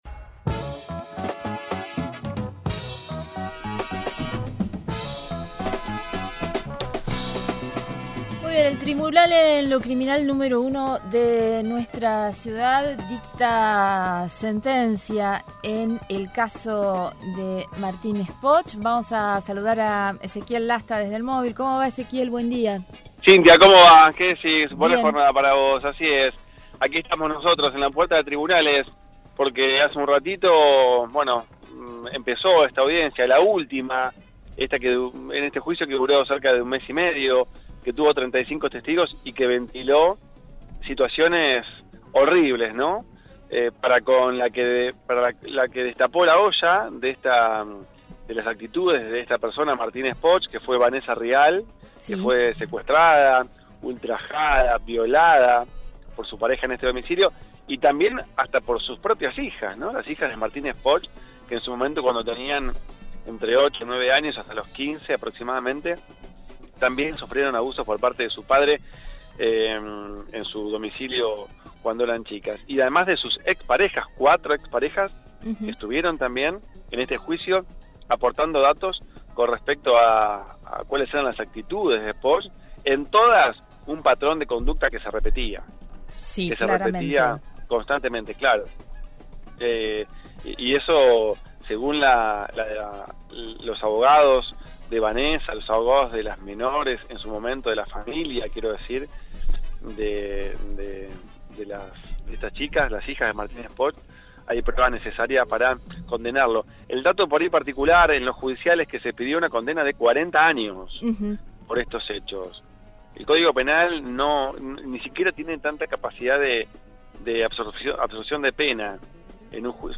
realizó un informe desde la sala del tribunal platense